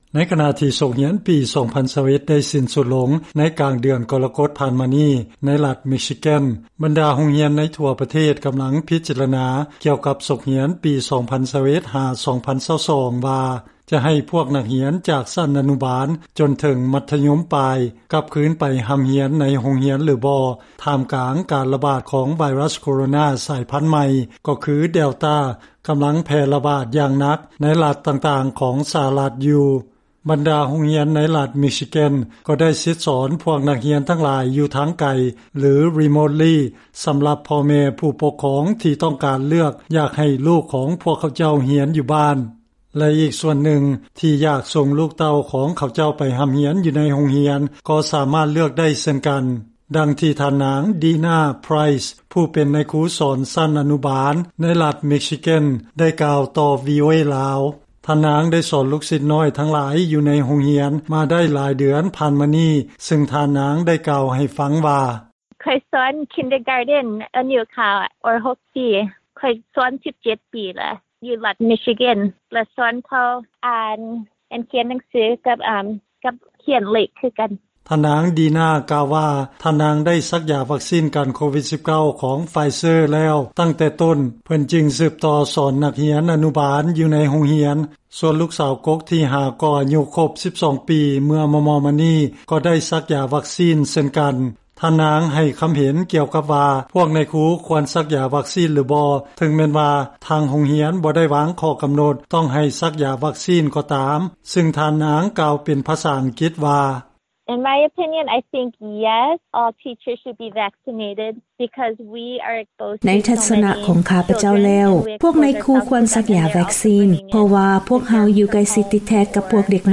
ເຊີນຟັງລາຍງານຂ່າວ ນາຍຄູຊັ້ນອະນຸບານ ອາເມຣິກັນເຊື້ອສາຍລາວ ກ່າວວ່າ ນາຍຄູຄວນສັກຢາວັກຊີນ ເພື່ອປ້ອງກັນຕົນເອງແລະພວກເດັກນ້ອຍ